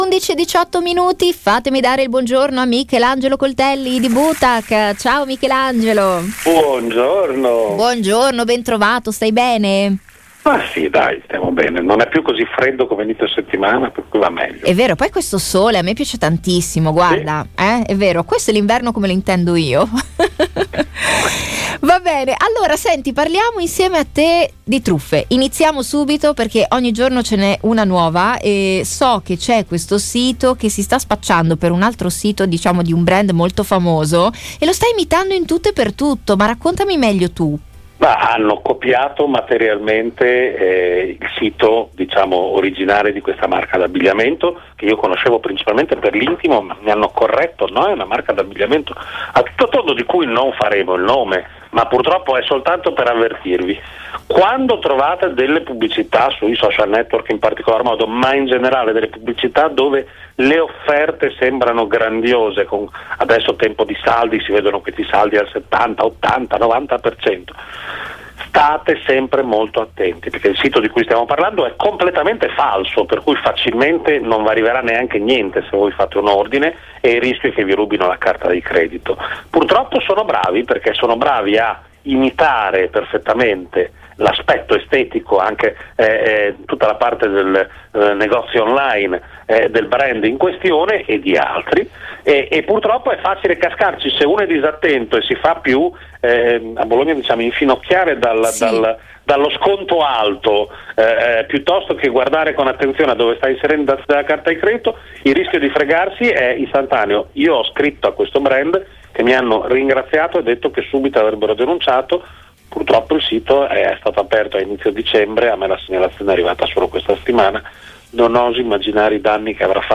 Nuove truffe e leggende urbane sono oggetto dell’intervista settimanale